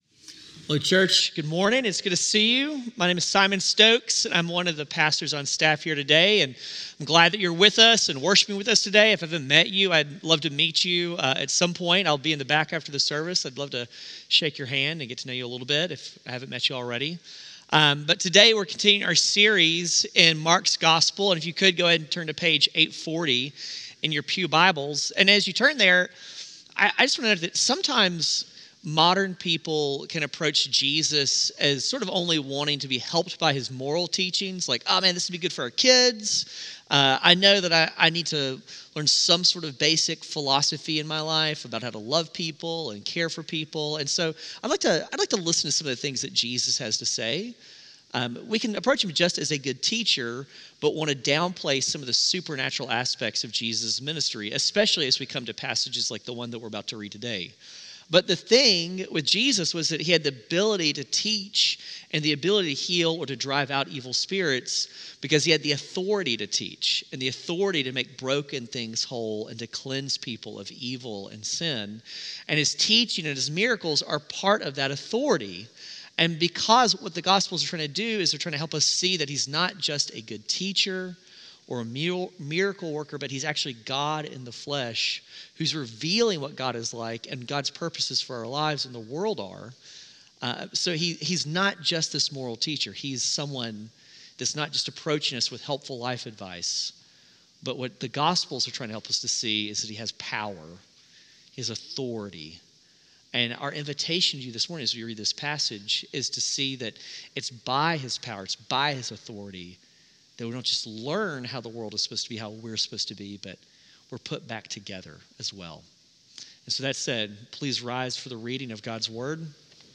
CGS-Service-2-15-26-Podcast.mp3